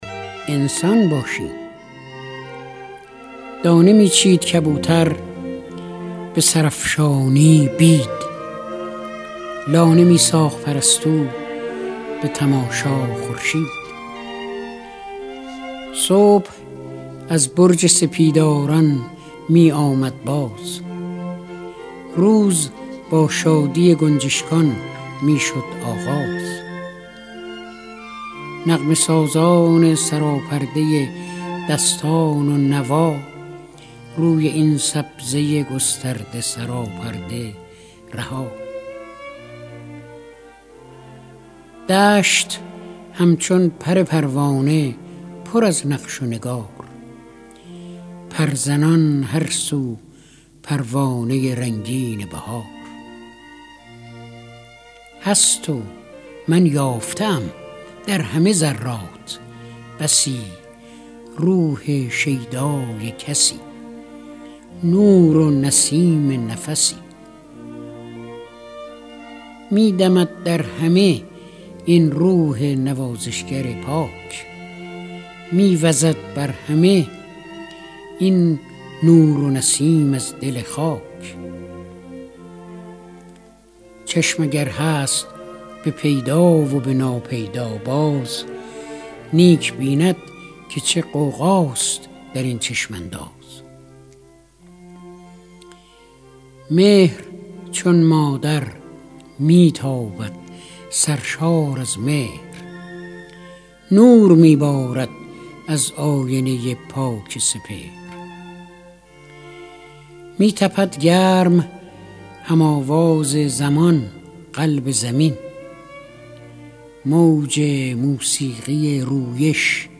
دانلود دکلمه انسان باشیم با صدای فریدون مشیری
گوینده :   [فریدون مشیری]